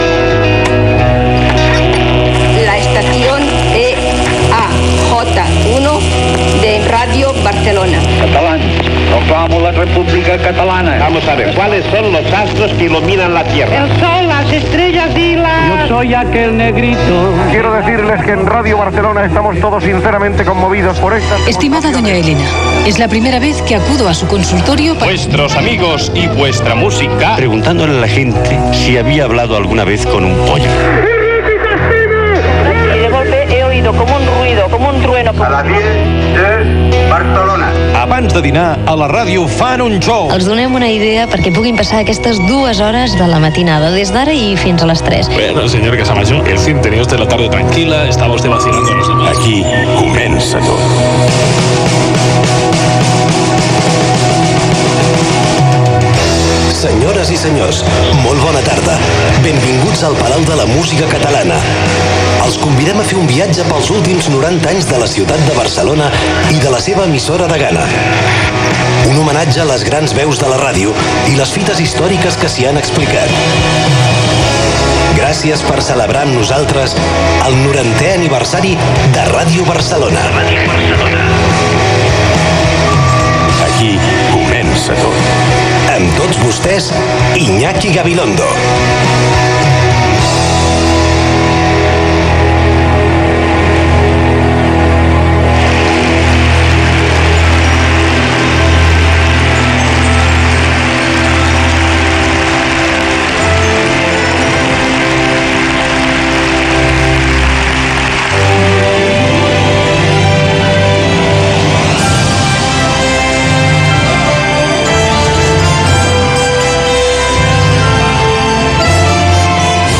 Iñaki Gabilondo presenta una gala des del Palau de la Música amb motiu del 90 aniversari de Ràdio Barcelona. Recull de sons del passat, sintonia de la cadena SER, salutació inicial
Entreteniment